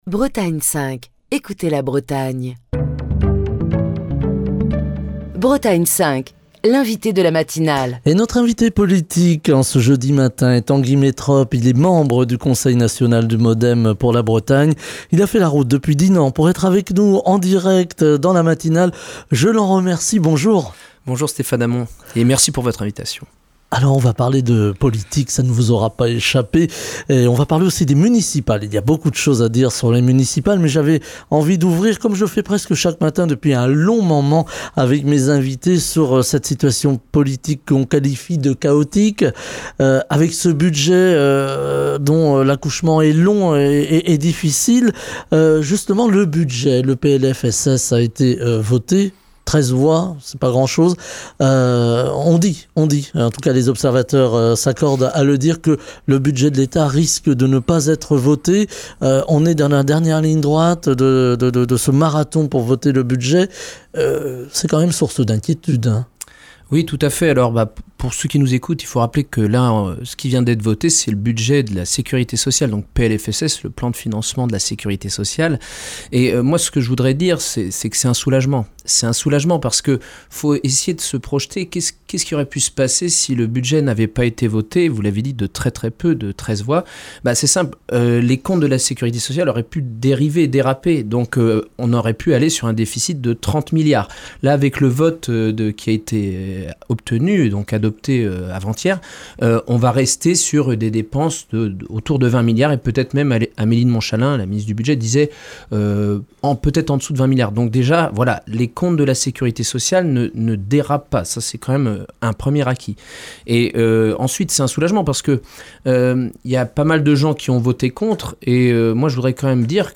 dans la matinale de Bretagne 5.